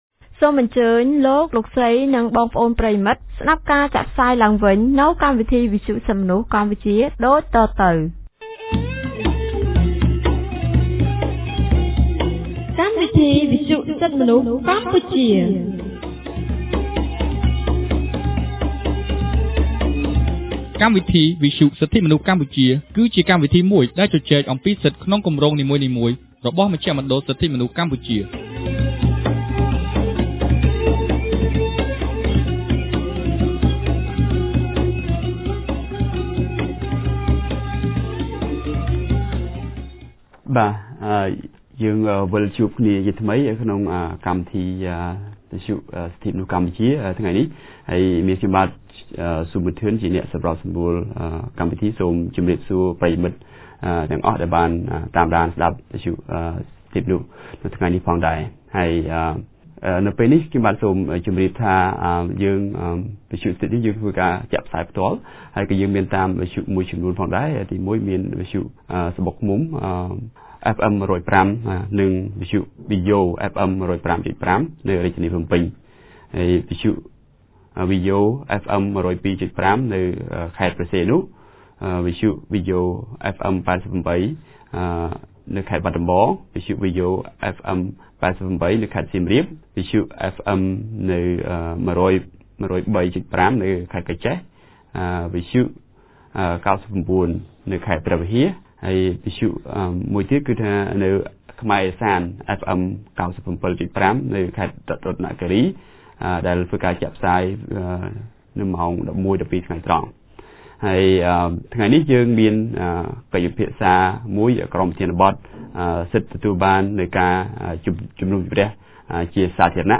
On 28 January 2015, CCHR TMP held a radio about Right to a Public Hearing.